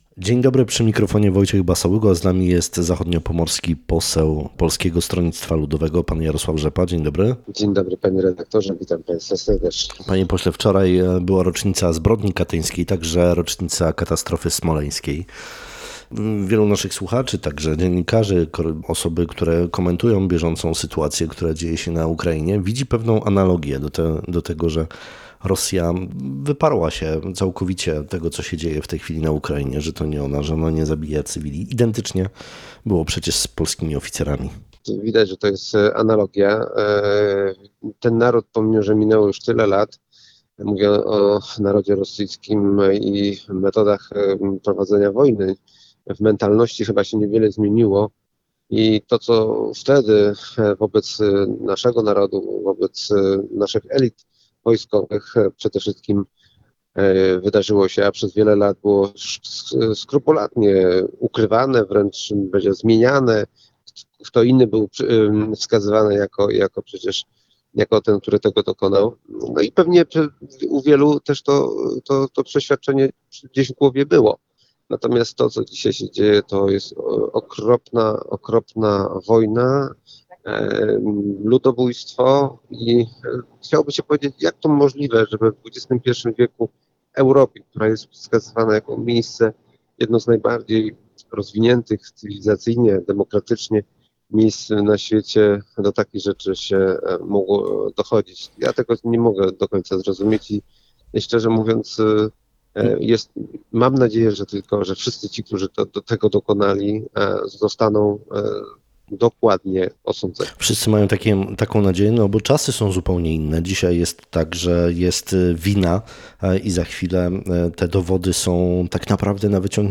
Naszym dzisiejszym gościem Rozmowy Dnia był zachodniopomorski poseł Polskiego Stronnictwa Ludowego, Jarosław Rzepa.